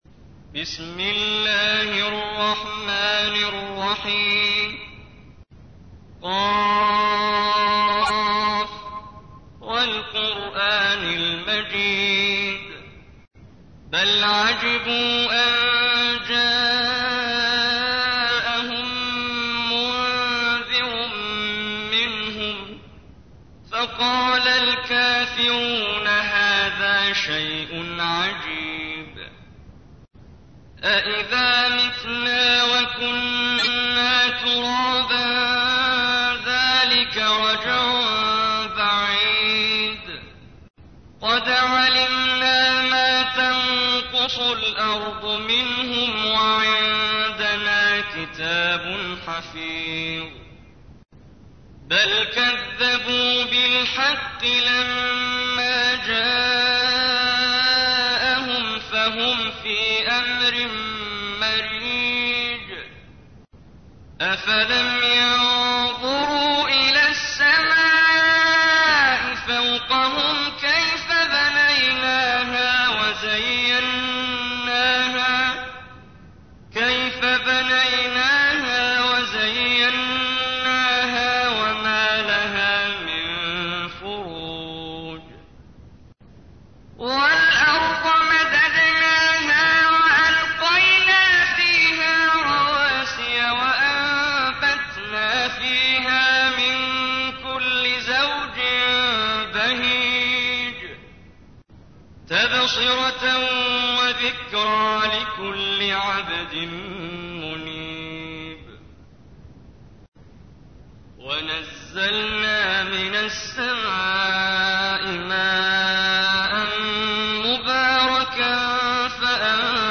تحميل : 50. سورة ق / القارئ محمد جبريل / القرآن الكريم / موقع يا حسين